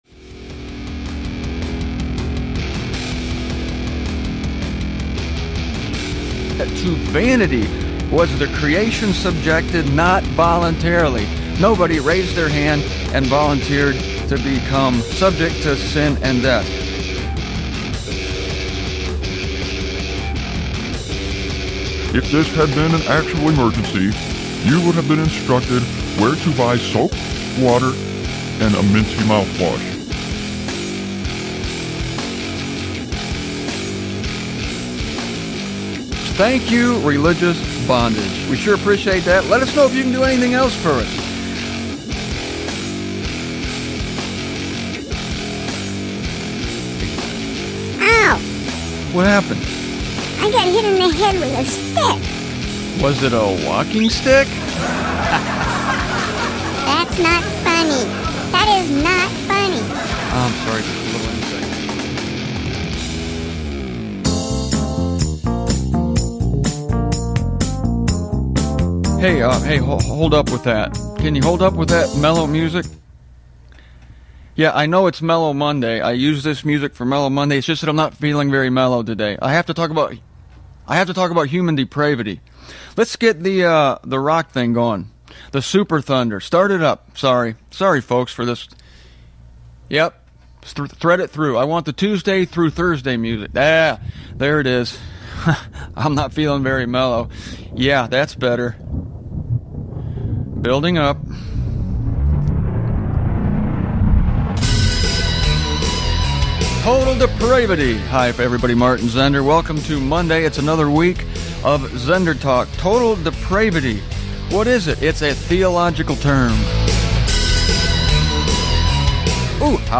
On to sexual topics, which are included at no extra cost to you in these five ZenderTalk shows, recorded around 2002.